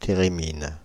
Ääntäminen
IPA: [te.ʁe.min] France (Île-de-France)